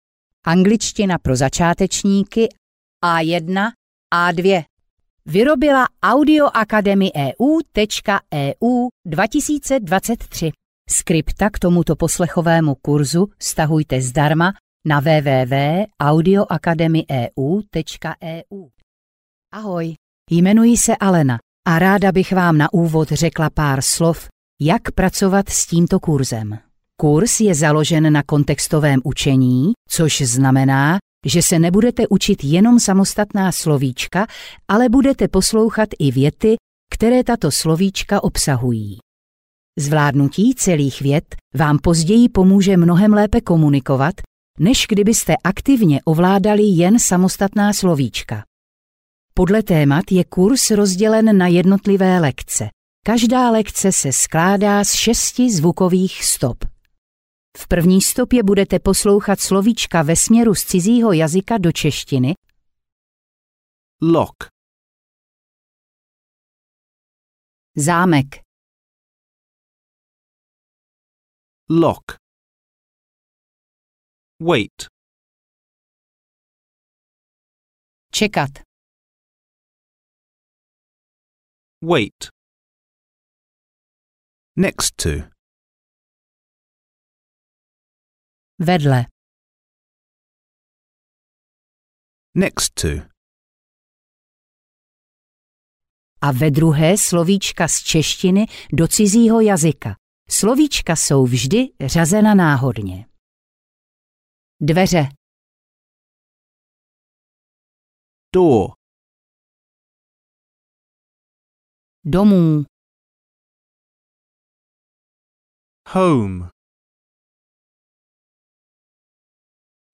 Angličtina pro začátečníky A1-A2 audiokniha
Ukázka z knihy